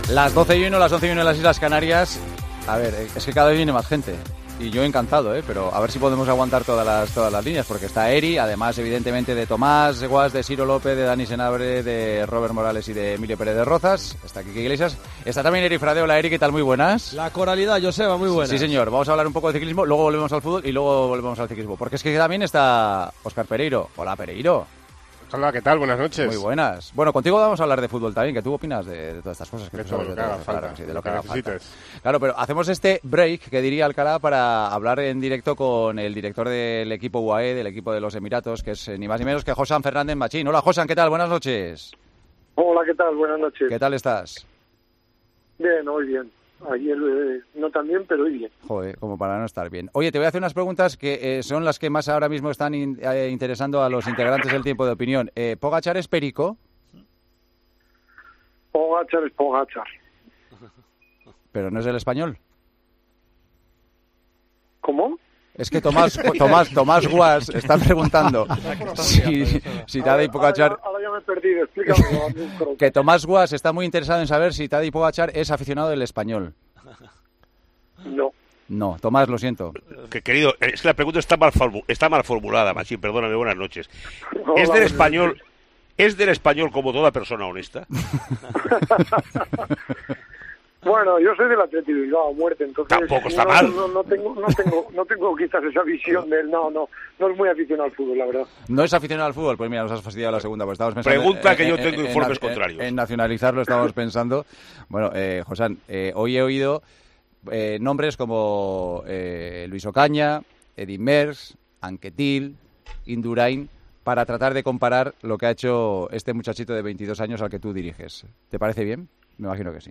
una entrevista en el tramo final de Tiempo de Juego